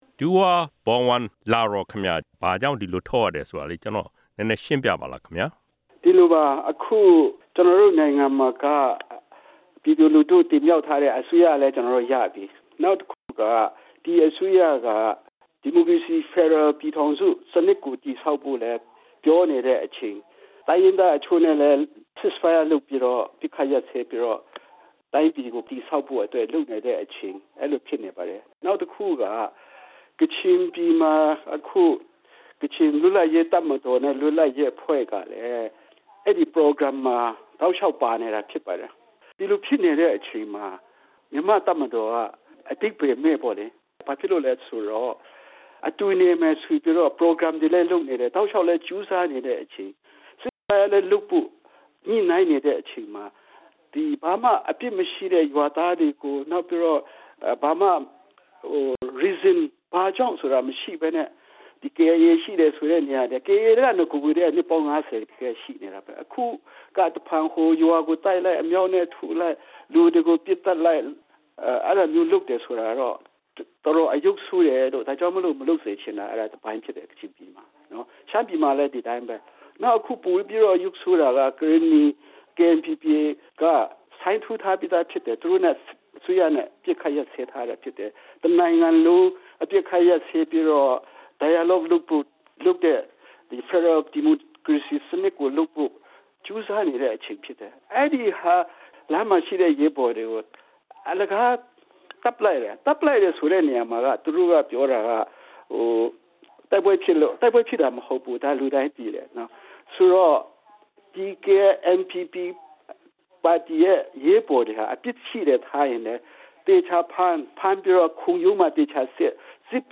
ဆက်သွယ်မေးမြန်းထားတာကို နားဆင်နိုင်ပါတယ်။